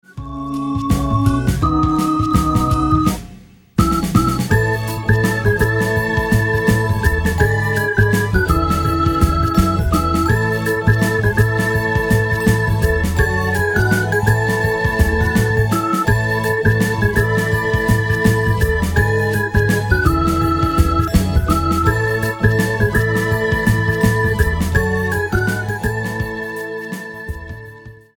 一応詳細を書いておくと、音源はやっと昨年末買ったMOTIF RACK ESのみで作成。
あと、いつものとおりレキシコンのMPX500で色づけ。
だからオルガンいっぱいな訳ですが･･･。